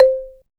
BALAPHONE C5.wav